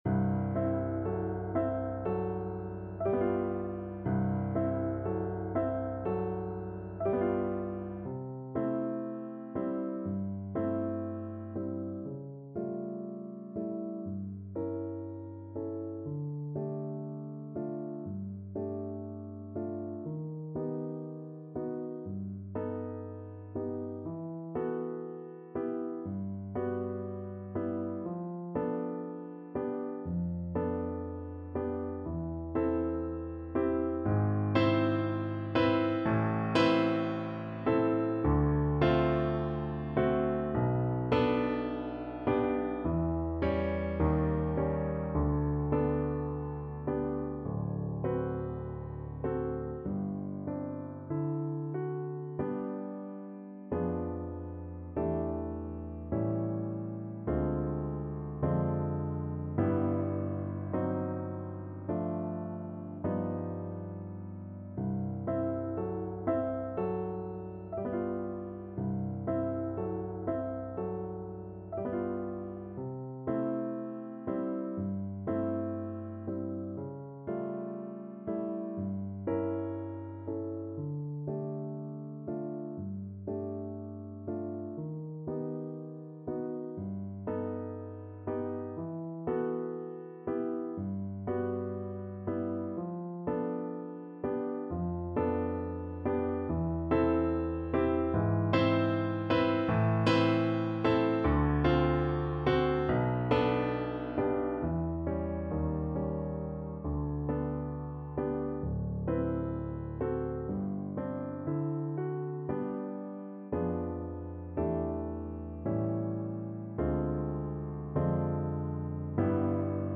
Classical (View more Classical Voice Music)